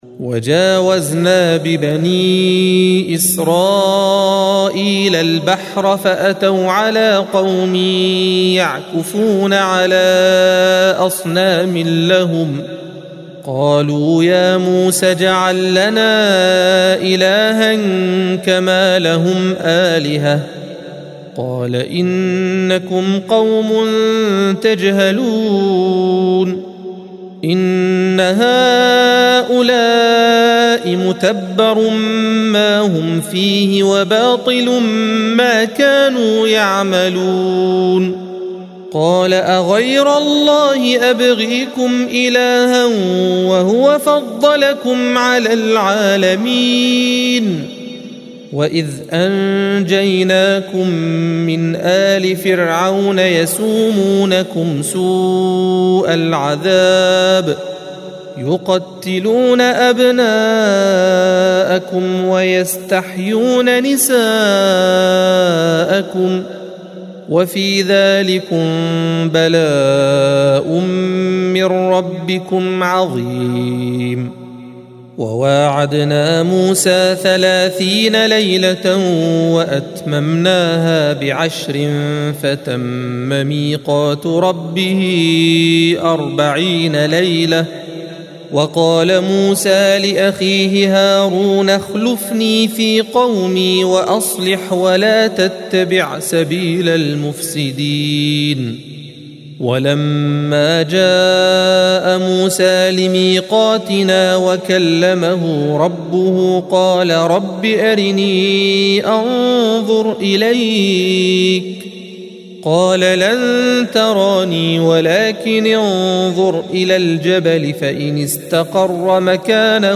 الصفحة 167 - القارئ